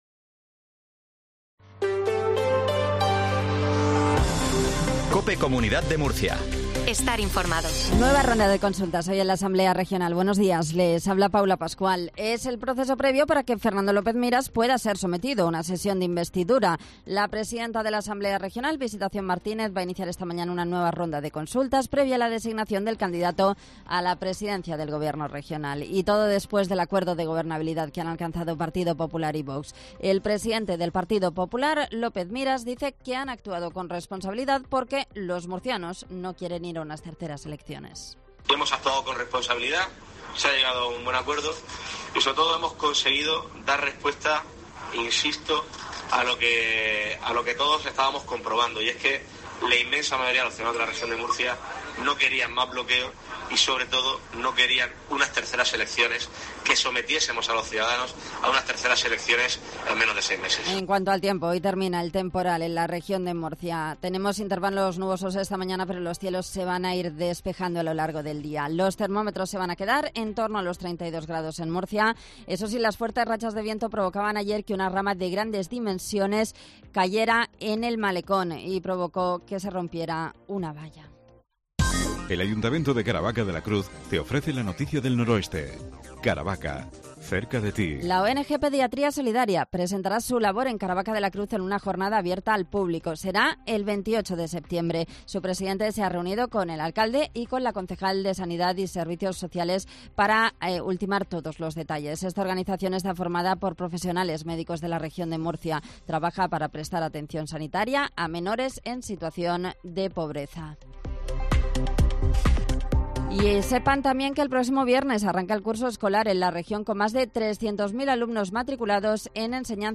INFORMATIVO MATINAL REGION DE MURCIA 0820